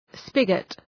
Προφορά
{‘spıgət}